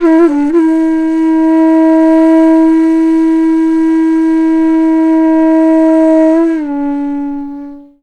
FLUTE-A04 -L.wav